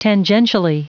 Prononciation du mot : tangentially
tangentially.wav